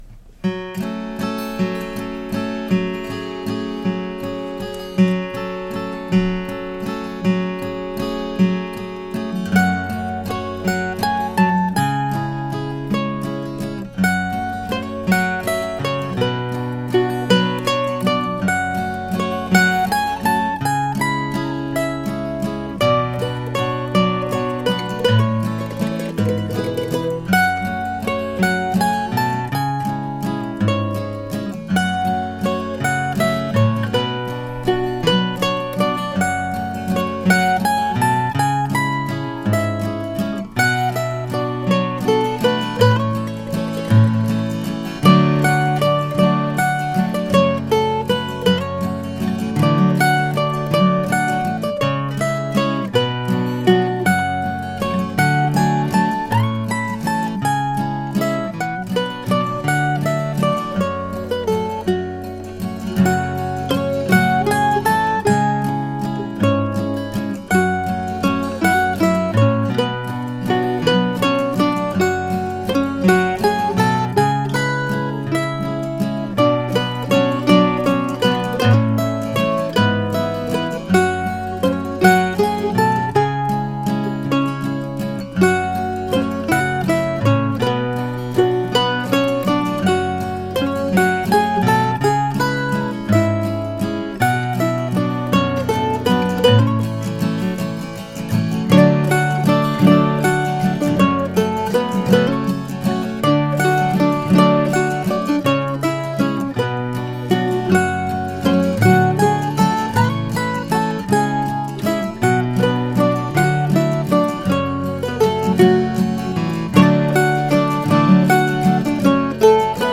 This simple waltz arrived last weekend as I was playing a lovely Martin 00-15M guitar for what proved to be the last time.
A fine guitar but I like the 00-18 even more. You can hear it on this track.
Nothing fancy, just a sweet little tune. Old Gibson A mandolin and a recent Weber Gallatin mandola on the second time through.